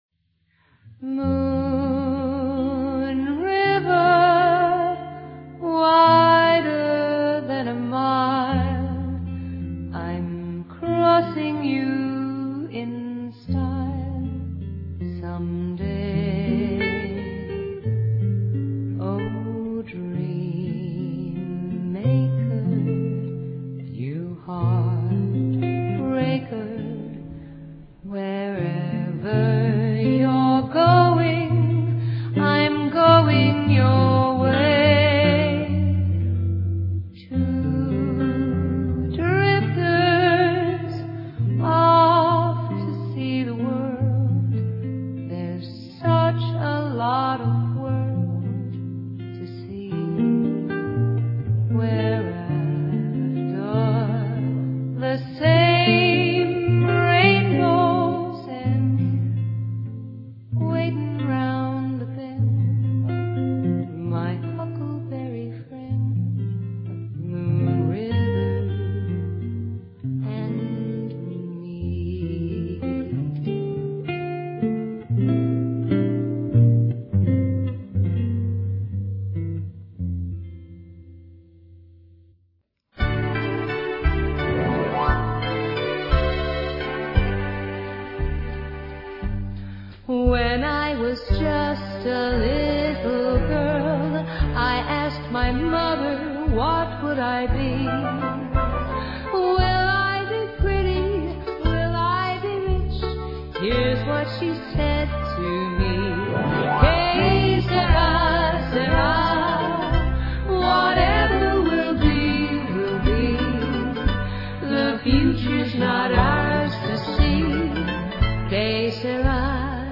Pop & Jazz hits